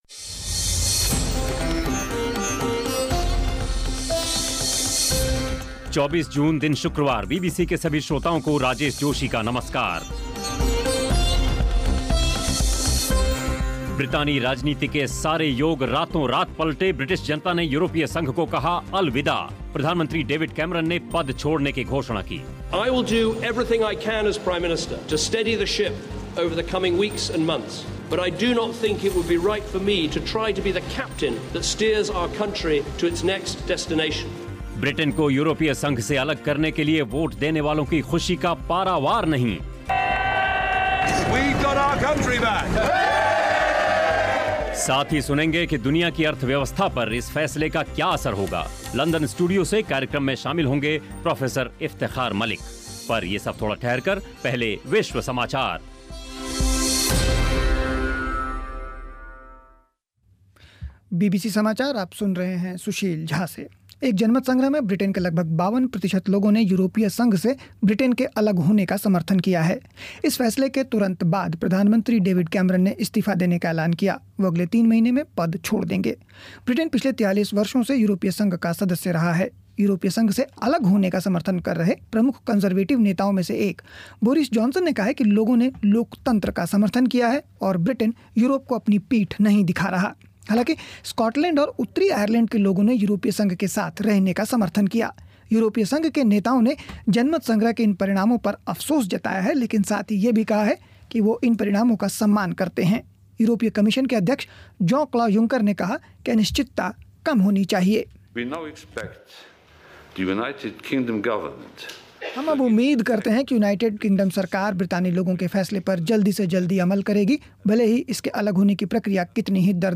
साथ ही सुनेंगे कि दुनिया की अर्थव्यवस्था पर इस फ़ैसले का क्या असर होगा? कार्यक्रम में लंदन स्टूडियो से